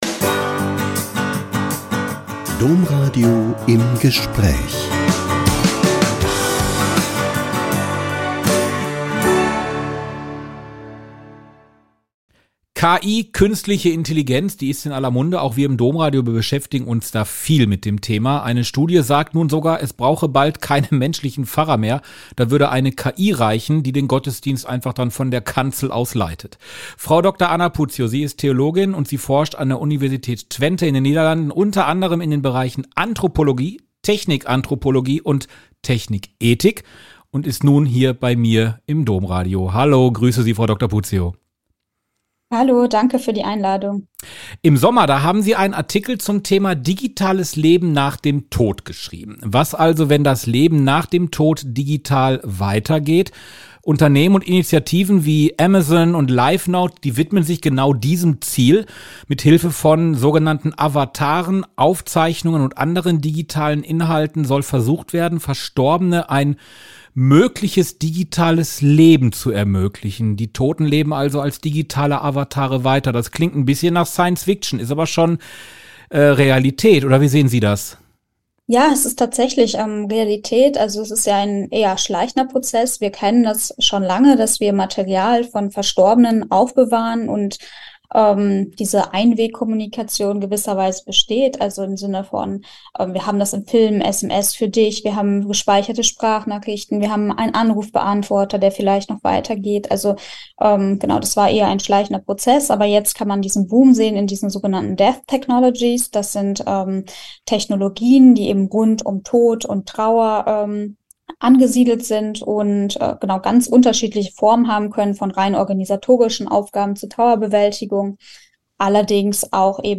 Wir haben mit Ihr über die Nutzung von Avataren nach dem Tode gesprochen.